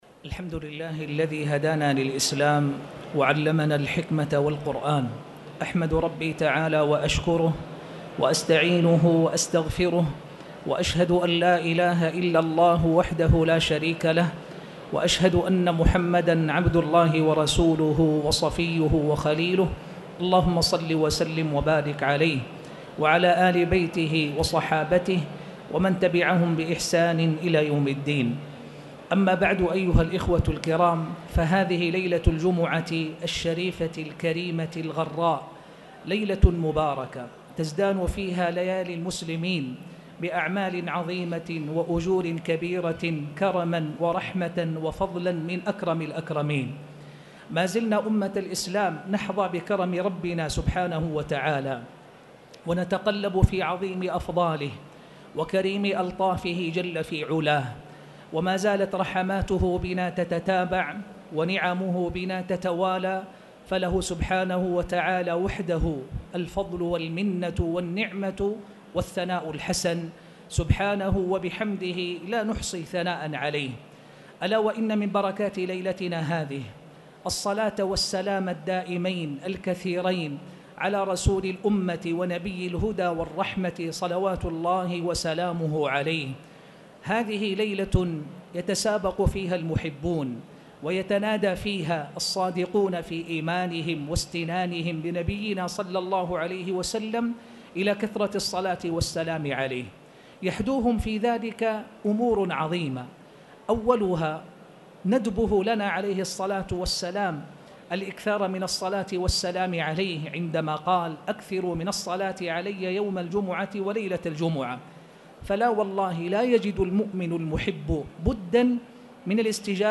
تاريخ النشر ٢٤ صفر ١٤٣٨ هـ المكان: المسجد الحرام الشيخ